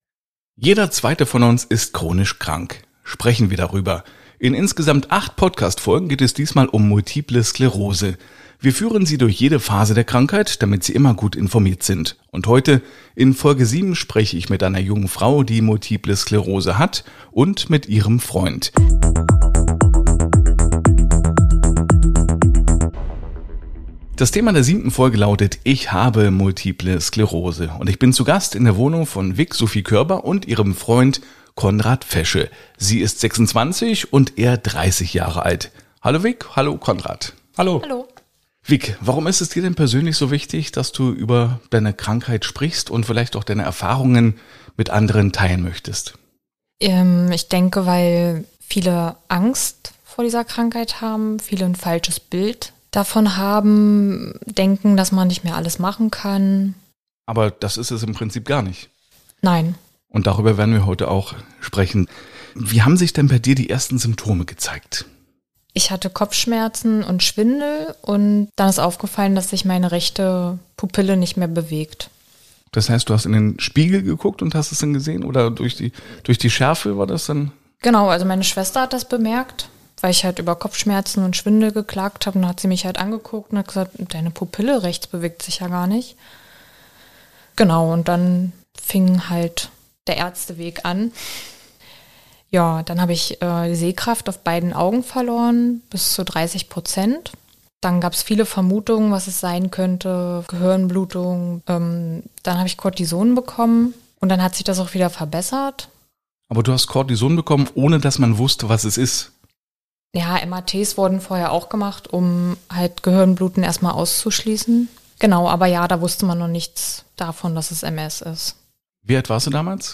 Darum und um vieles mehr, geht es in diesem Interview.